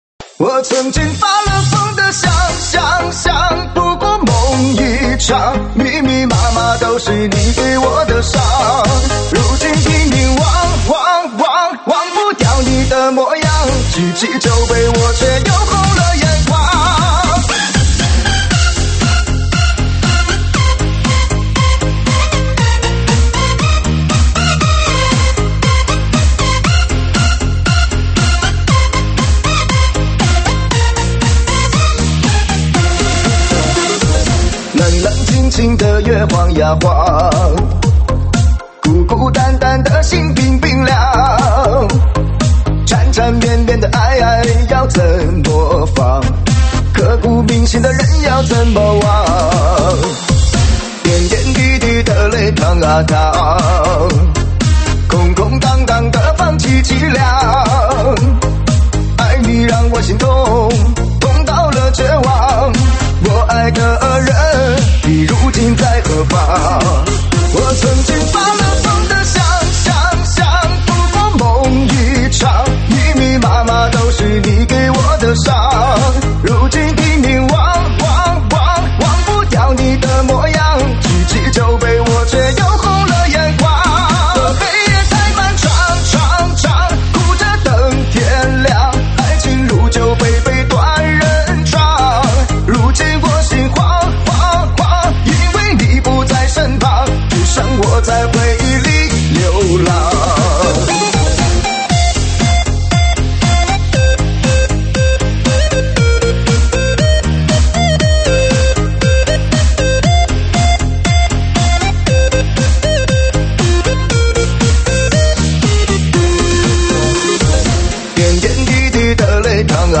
舞曲类别：现场串烧